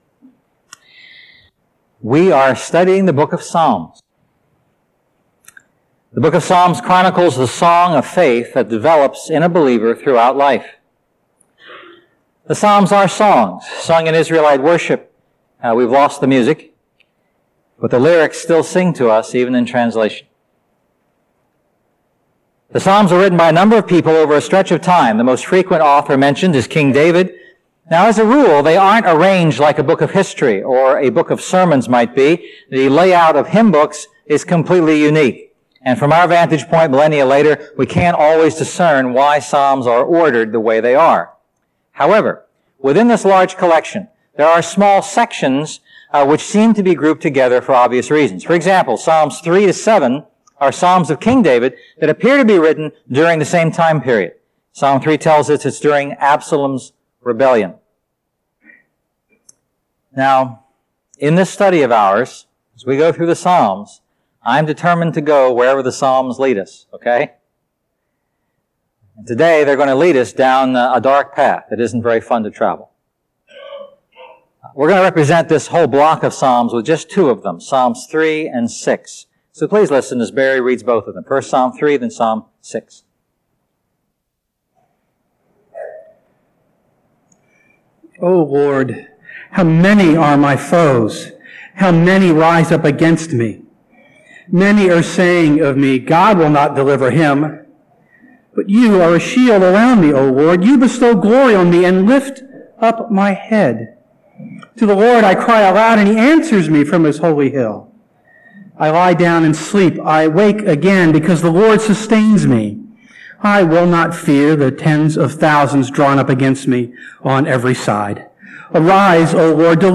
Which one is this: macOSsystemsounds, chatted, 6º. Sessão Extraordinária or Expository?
Expository